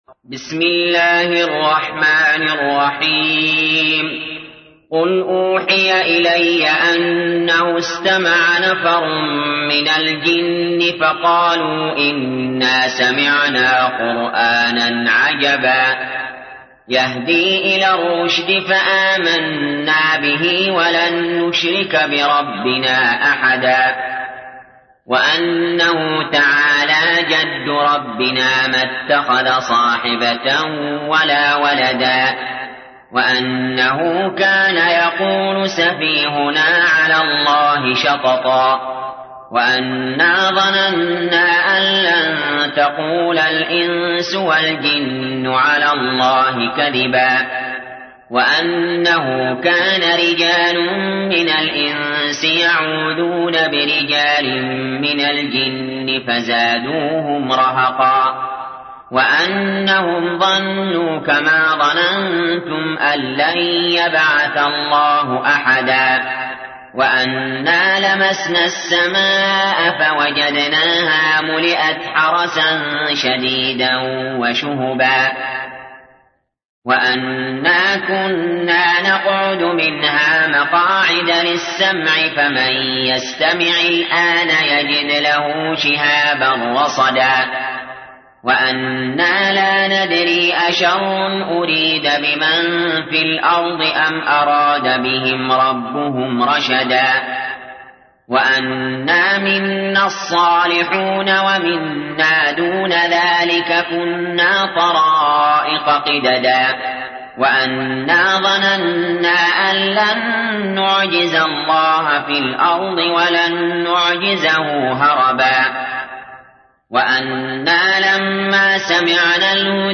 تحميل : 72. سورة الجن / القارئ علي جابر / القرآن الكريم / موقع يا حسين